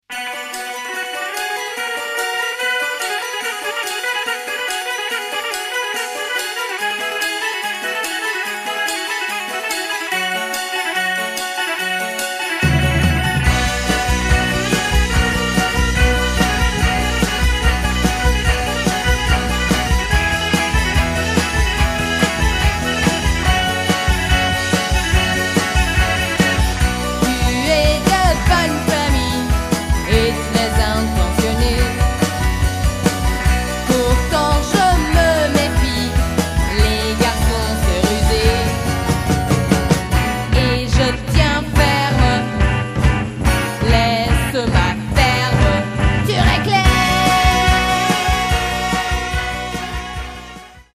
französischen Sixties-Beat, charmante Pop-Songs